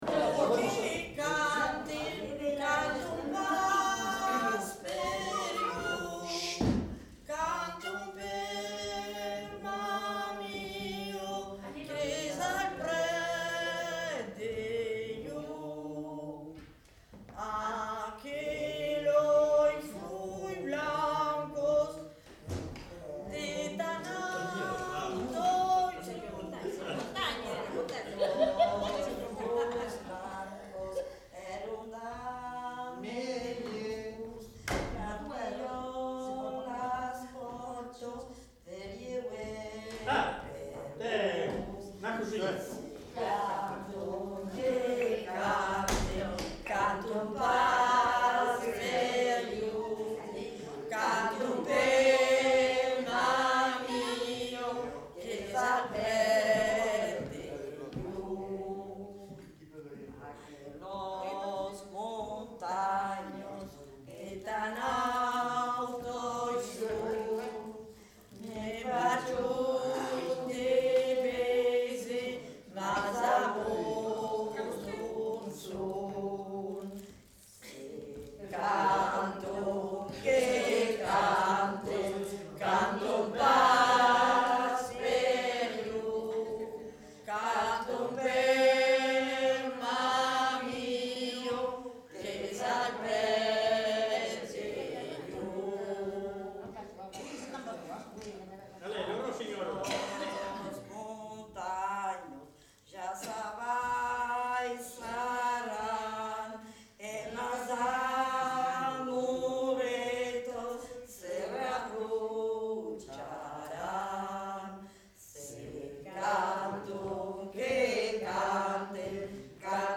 Aire culturelle : Lauragais
Lieu : Lanta
Genre : chant
Effectif : 1
Type de voix : voix de femme
Production du son : chanté
Description de l'item : fragment ; 3 c. ; refr.